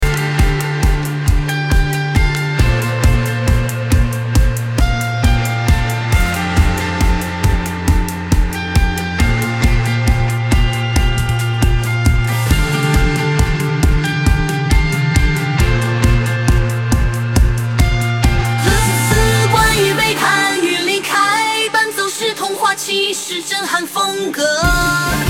伴奏是童话骑士震撼风格